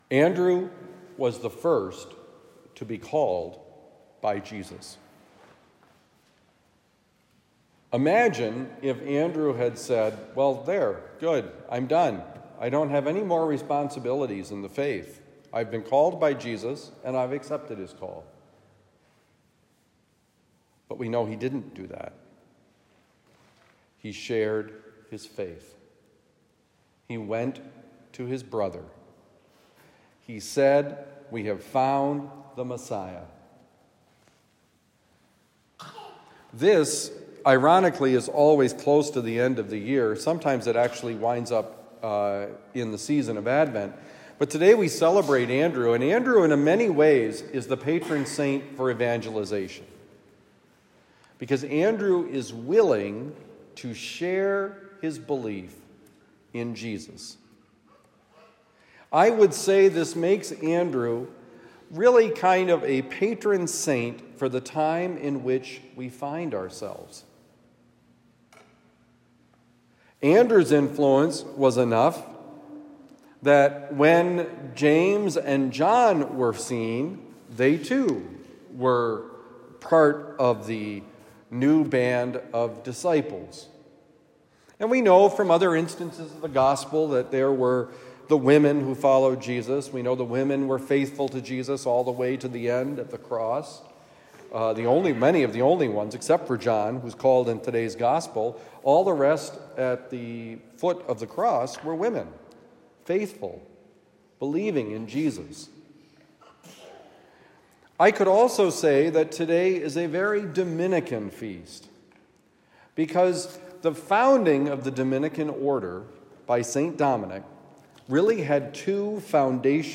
Watch! Be alert! Homily for the First Sunday of Advent, December 3, 2023